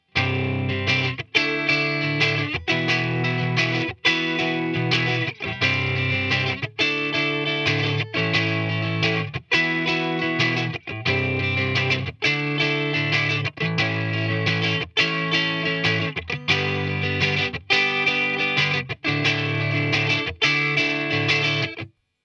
Цепь записи: Fender Telecaster California -> Chris Custom Combo -> Shure SM85 смотрящий в динамик   AKG C1000 в метре от него -> Рековая звуковая карта MOTU 828 MkII
Предисловие - семплы выдраны прямо из песни. Звук "без всего" суховат, но в микс все ложиться прекрастно  ;)
wav или mp3) - Этот семпл показывает естественный перегруз чистого канала, при громкости чистого и общей громкости, близкой к максимальным. Очень приятный, мягкий и теплый тембр. Опять же - нековый датчик.
NaturalBoost.WAV